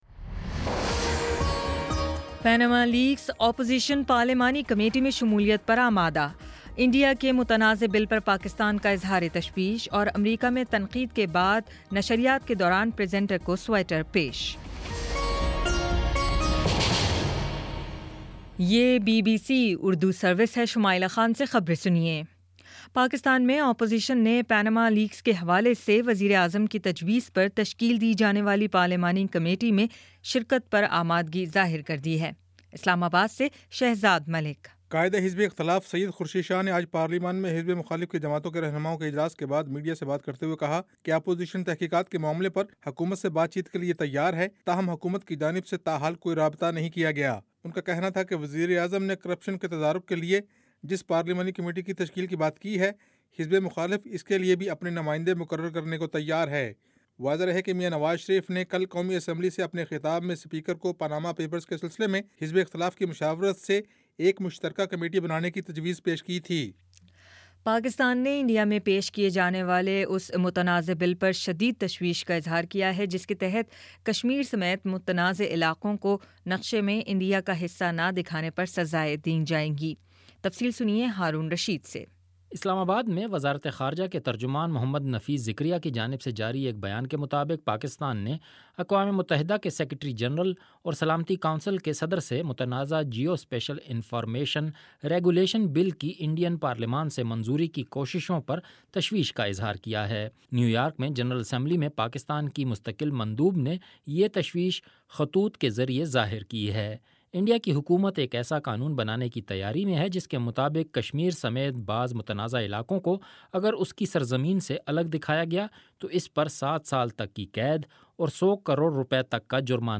مئی 17 : شام پانچ بجے کا نیوز بُلیٹن